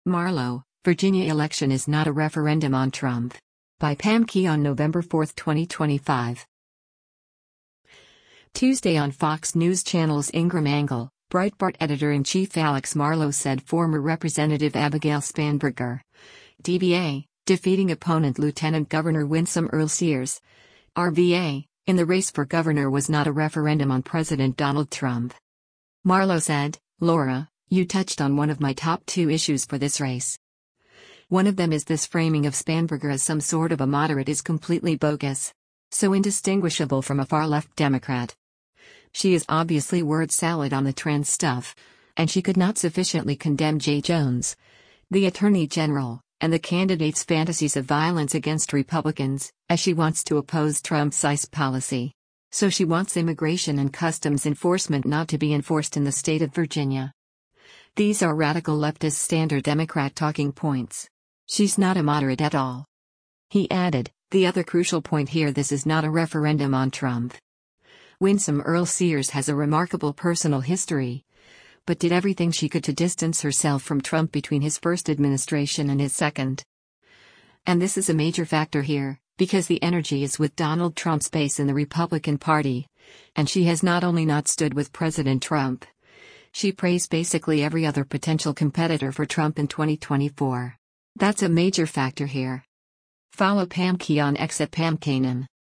Tuesday on Fox News Channel’s “Ingraham Angle,” Breitbart Editor-in-Chief Alex Marlow said former Rep. Abigail Spanberger (D-VA) defeating opponent Lt. Gov. Winsome Earle-Sears (R-VA) in the race for governor was “not a referendum” on President Donald Trump.